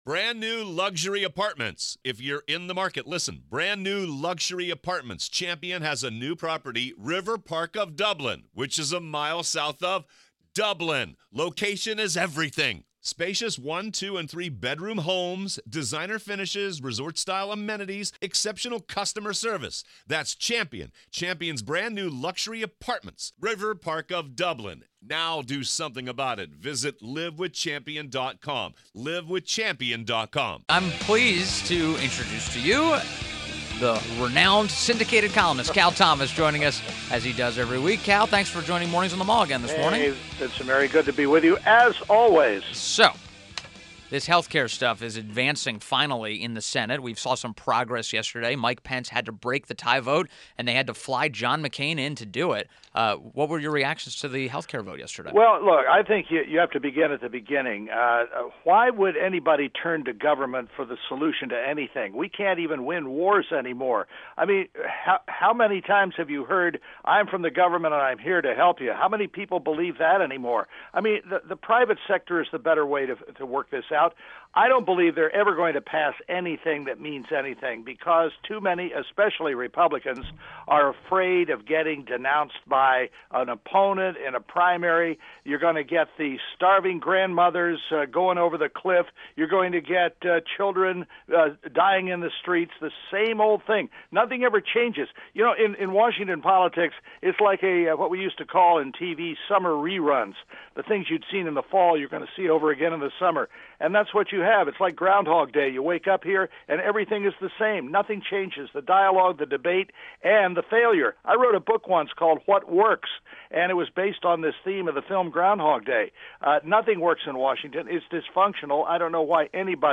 INTERVIEW - CAL THOMAS - syndicated columnist